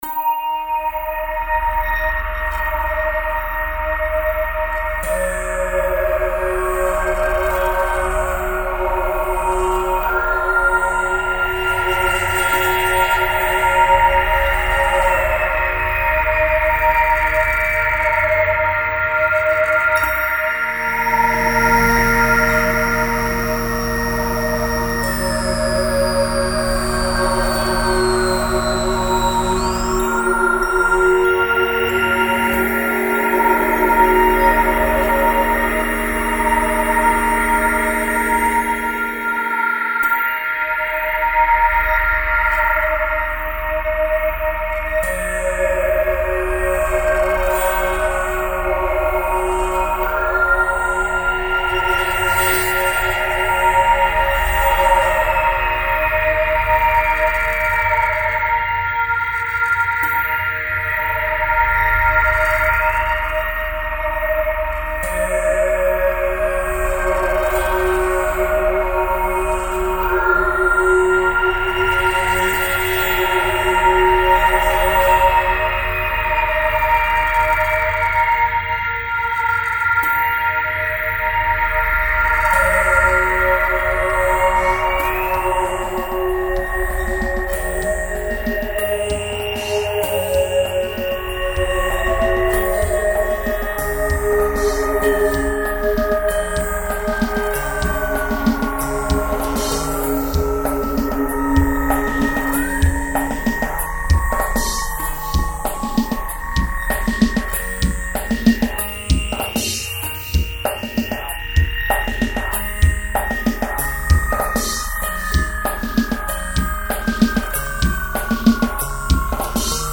It was born out of the electric koto sound you can now hear a little less, and has developed from there.
• 1:20 Intro develops
• 2:00 Piano is introduced
• 2:20 New melody ( should be piano only - I think that synh is too waily)
• 3:20 Introduction of new, angular line...new drum beat...piano ostinato...